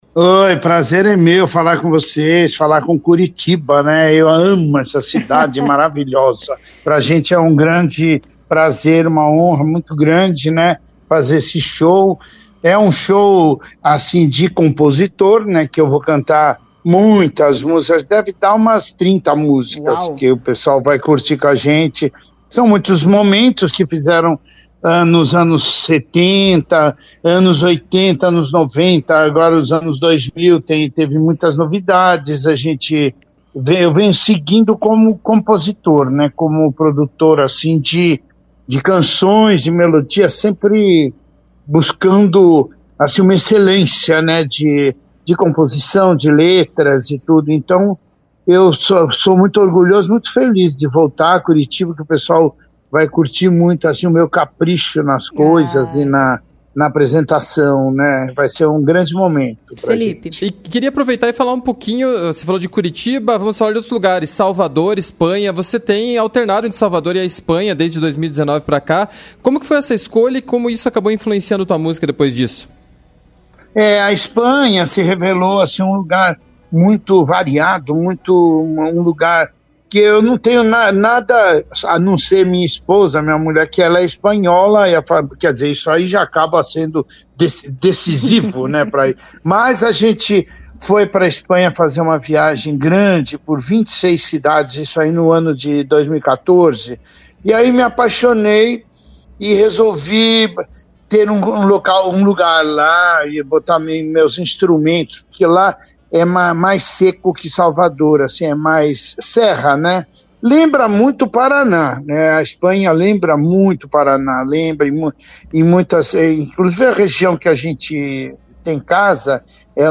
Em entrevista à CBN Curitiba, o cantor Guilherme Arantes fala sobre essa apresentação.
Entrevista-7-11-2.mp3